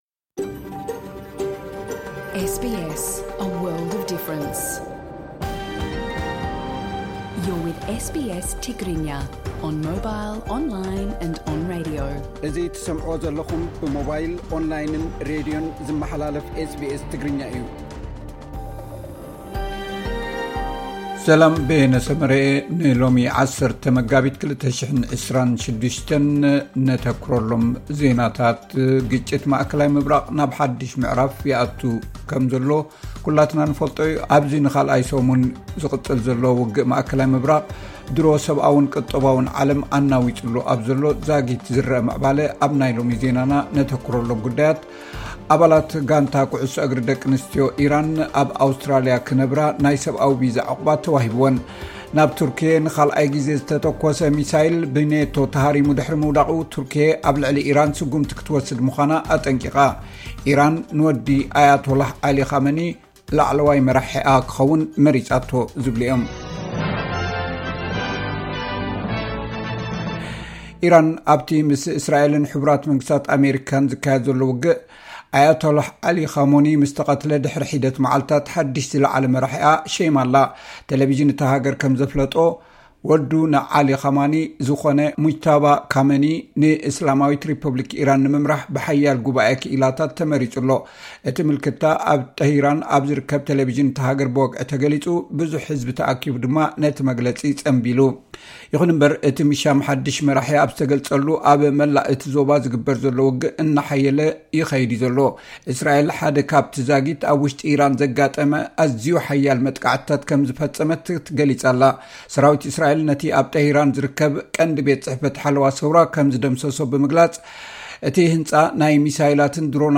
ኣባላት ጋንታ ኲዕሶ እግሪ አቂኣንስትዮ ኢራን ኣብ ኣውስትራሊያ ክንብራ ናይ ሰብኣዊ ቪዛ ዑቕባ ተዋሂብውን (ሓጸርቲ ዜናታት 10 መጋቢት 2026)